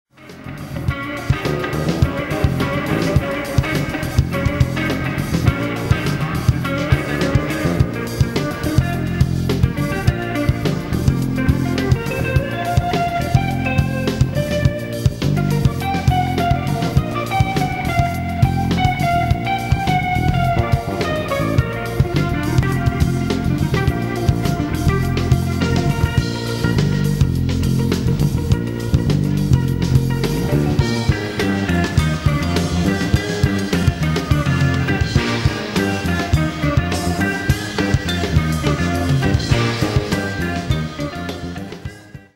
LIVE AT MESTRE, ITALY
SOUNDBAORD RECORDING